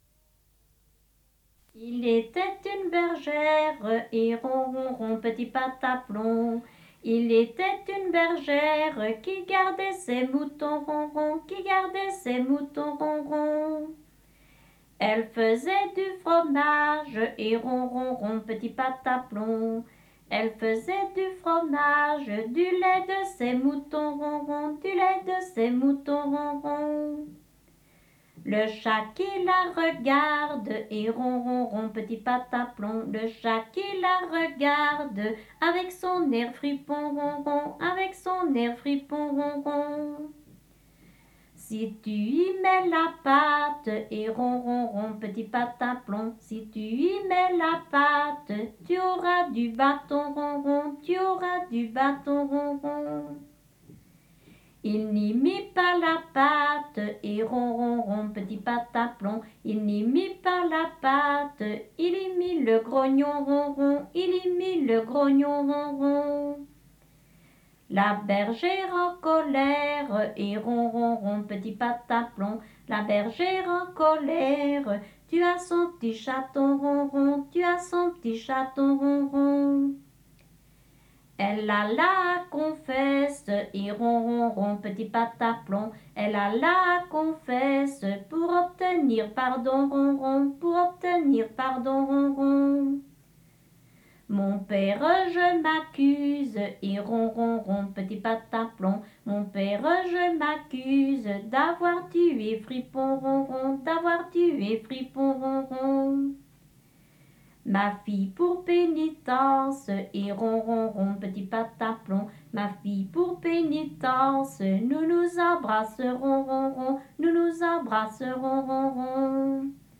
Genre : chant
Type : chanson narrative ou de divertissement
Lieu d'enregistrement : Lantin (Juprelle)
Support : bande magnétique